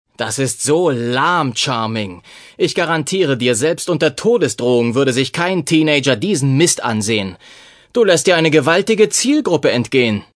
Artie:
"Praiseworthy voice recordings"